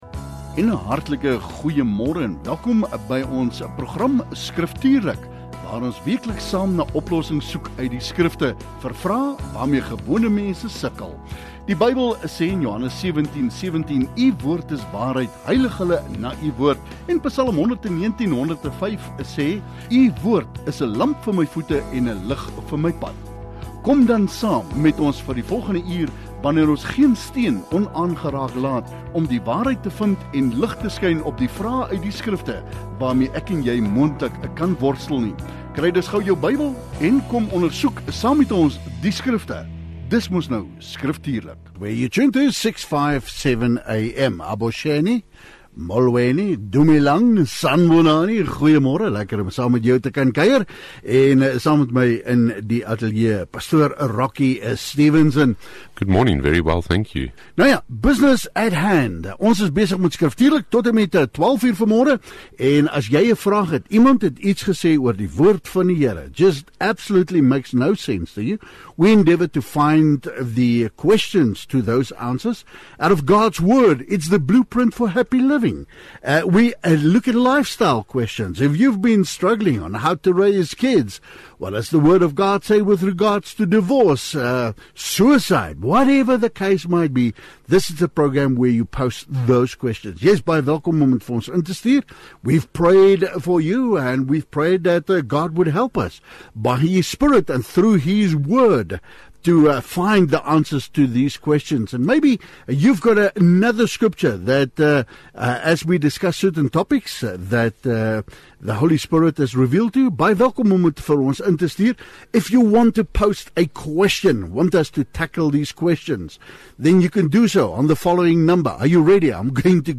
Luisteraars stuur vrae direk na die ateljee via WhatsApp.